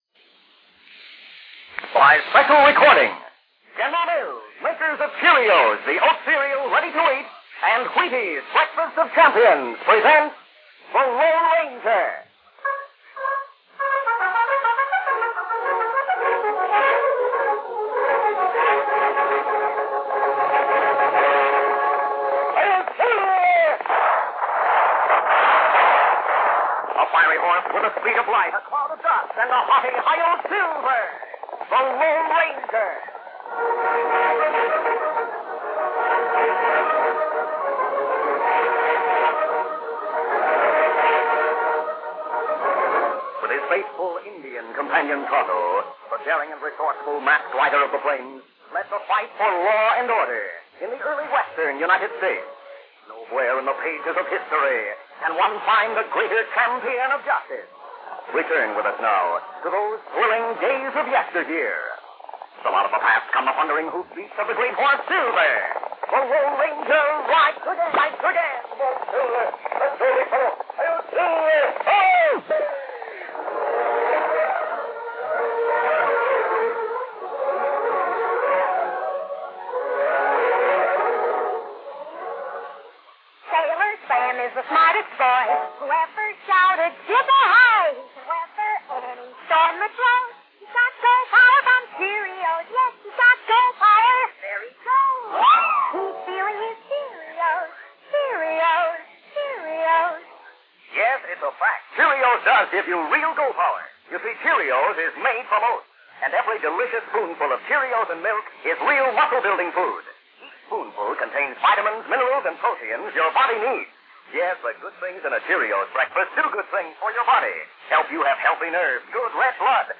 OTR Christmas Shows - The Three Wise Men with noise reduction - 1952-12-25 WXYZ The Lone Ranger